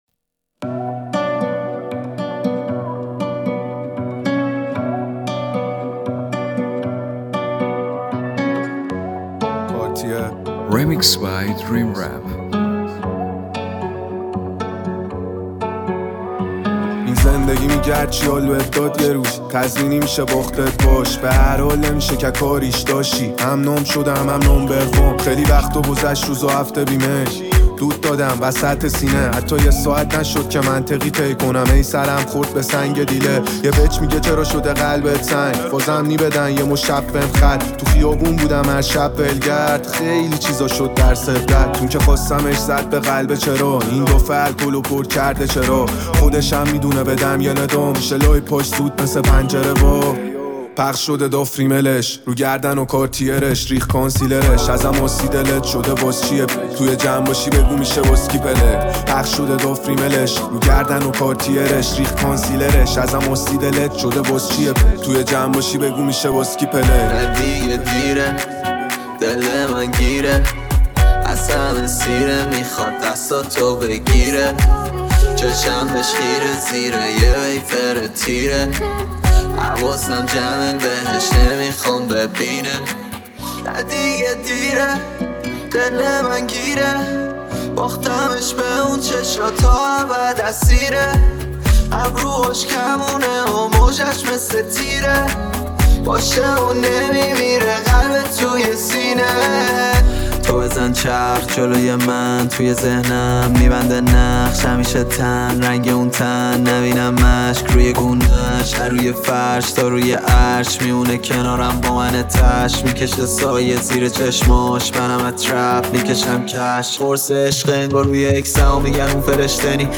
ریمیکس جدید رپ
Remix Rapi